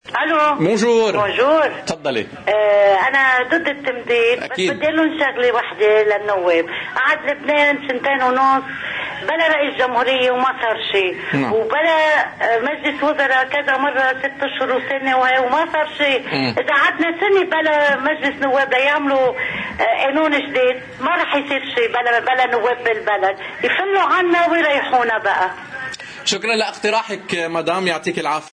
ضمن الإتصالات الهاتفية التي انهالت على قناة الـ”OTV” ضمن برنامج “أجندة اليوم”، اتصلت إحدى السيدات سائلة: